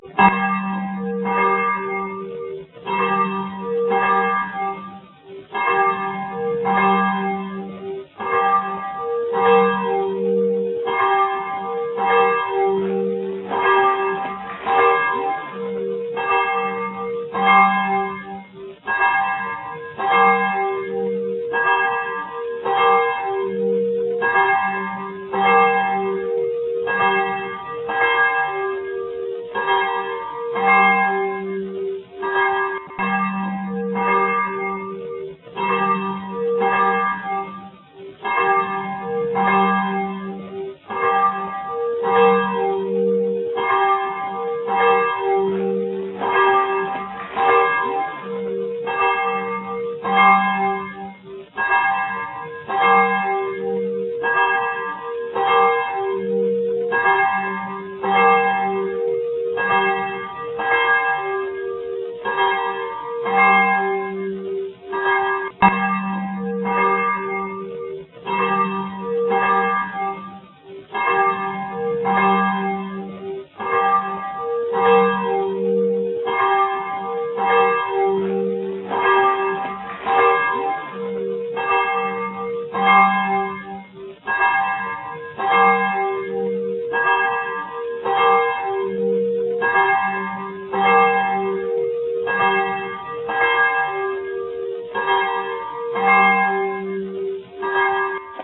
La cloche de Moussy pèse environ 600 kg.
Battant de type rétro-lancé avec contrepoids.
L'angelus
par Cloche Charles-anne
ClocheDeMoussy1.mp3